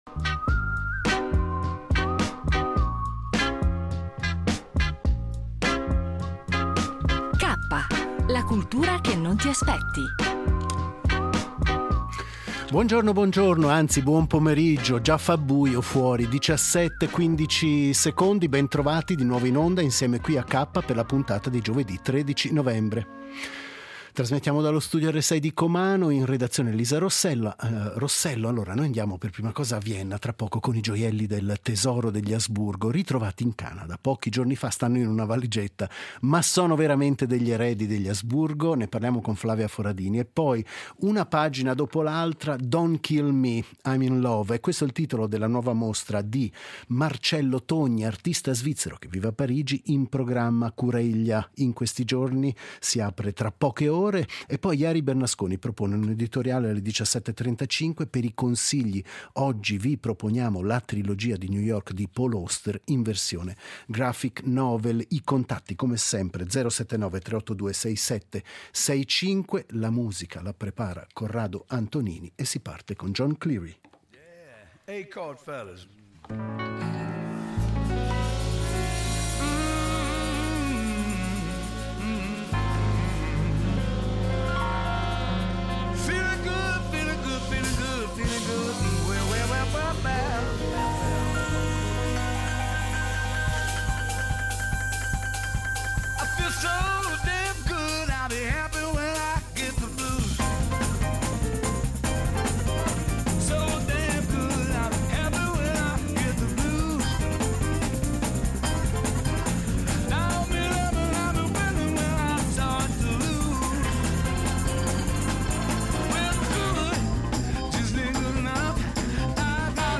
Al centro, la trilogia di New York di Paul Auster in graphic novel, illustrata da Mattotti e altri autori, occasione per riflettere sul valore del medium. In chiusura, un’intervista sul documentario Banksy e la ragazza del Bataclan.